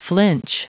Prononciation du mot flinch en anglais (fichier audio)
Prononciation du mot : flinch